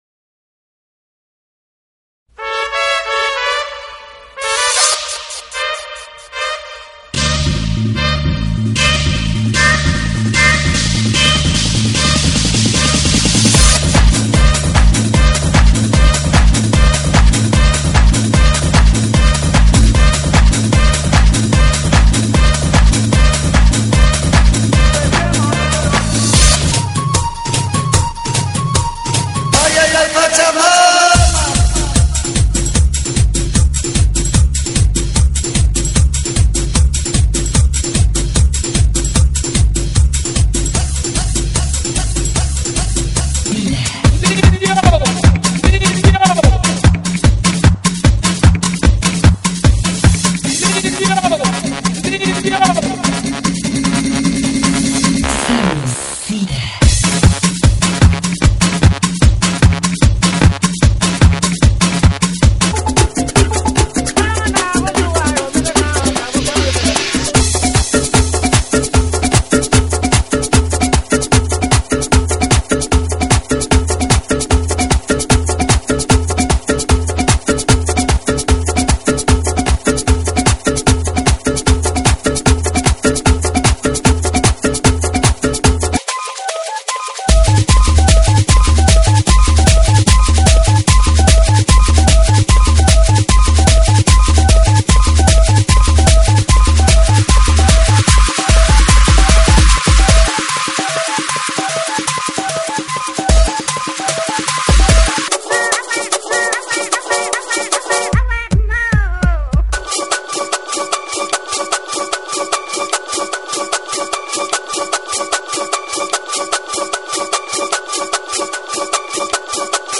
GENERO: TRIBAL – HOUSE
TRIBAL HOUSE,